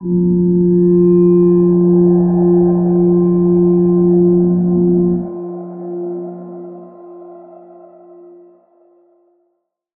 G_Crystal-F4-pp.wav